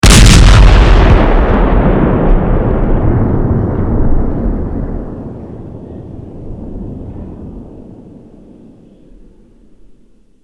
Реалистичные эффекты с хорошей детализацией низких частот.
Оглушительный грохот пушечного выстрела